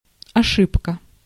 Ääntäminen
Tuntematon aksentti: IPA: /ɐˈʂɨpkə/